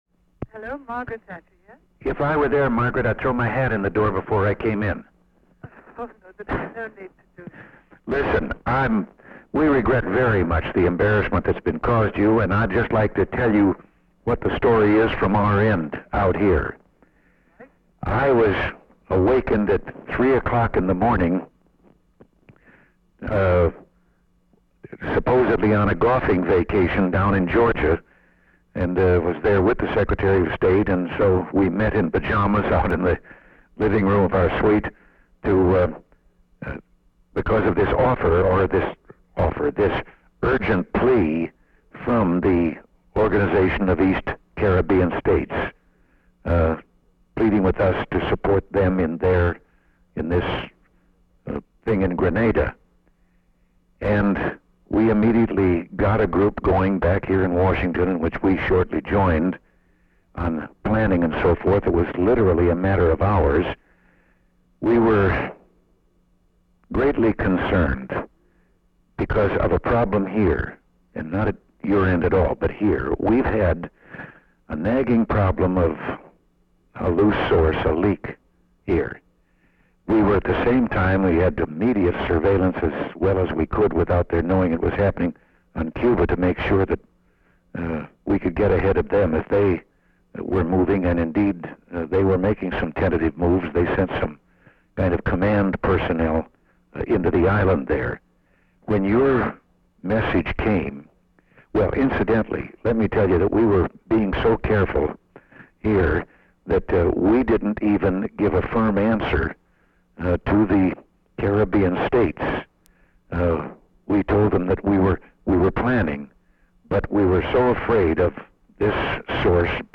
Date: October 26, 1983 Location: Situation Room Tape Number: Cassette 2A Participants Ronald W. Reagan Margaret Thatcher Associated Resources Annotated Transcript Audio File Transcript